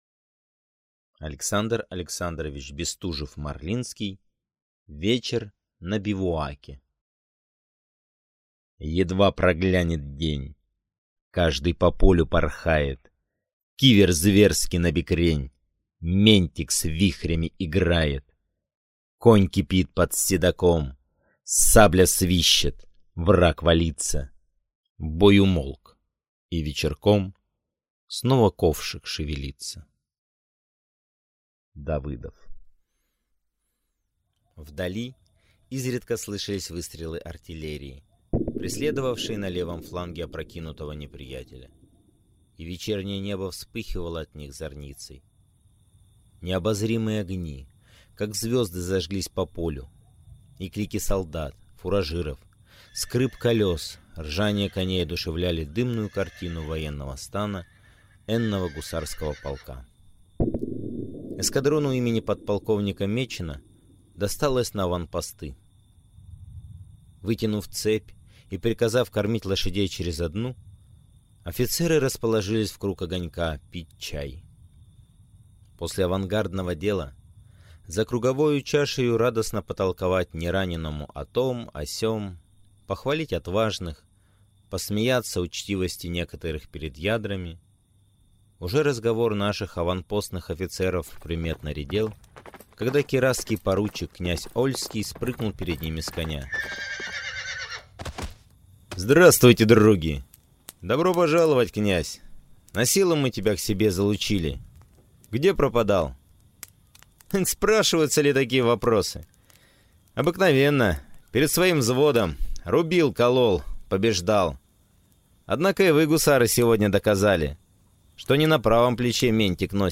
Аудиокнига Вечер на бивуаке | Библиотека аудиокниг